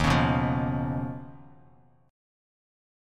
Eb7sus2#5 chord